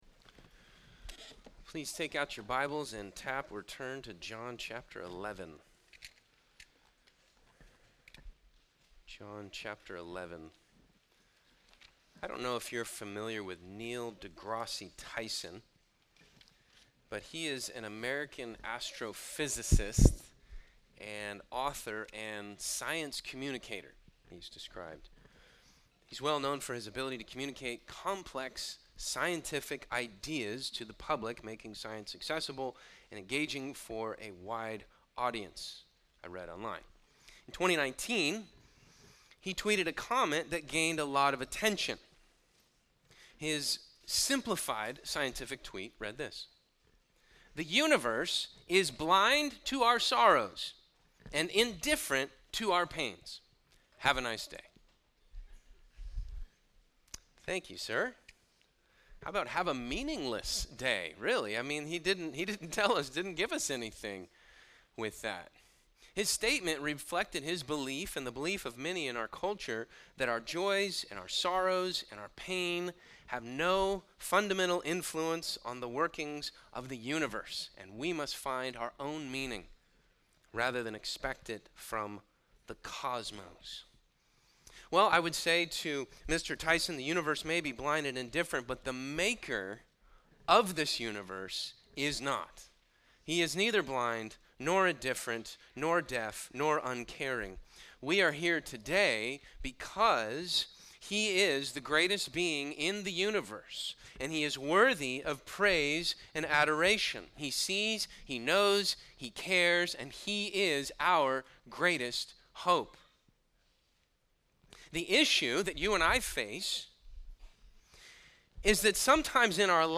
From Series: "All Sermons"